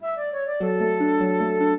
flute-harp
minuet11-8.wav